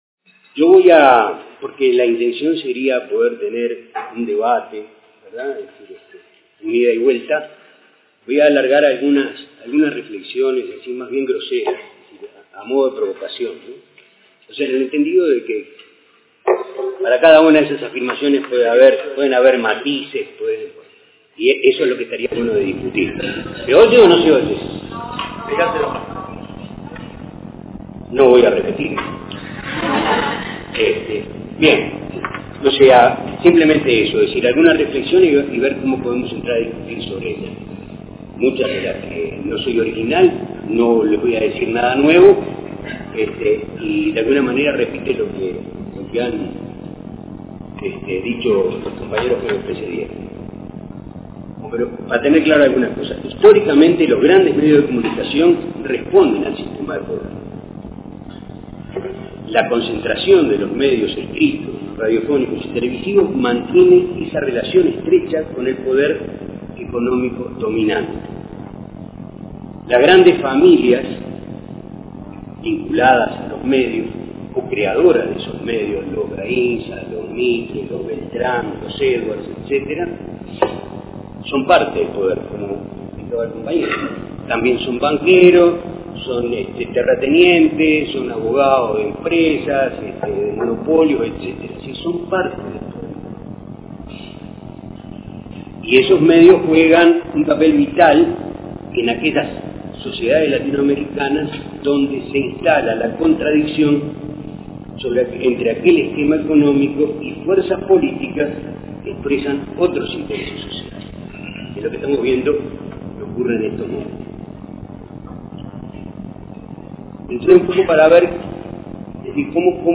Estos audios no poseen la calidad de los de días anteriores del evento, sepan disculparnos por esto, se debe a que la amplificación y la acústica del lugar no eran las adecuadas. De todas formas han sido mejorados en la medida de lo posible para facilitar la escucha.